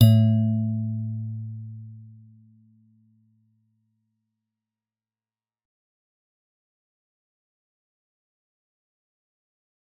G_Musicbox-A2-f.wav